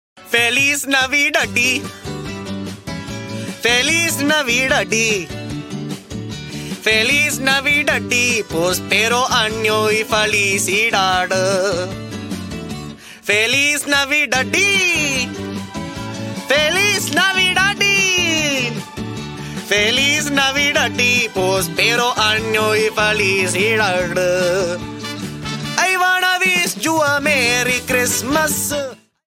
indian version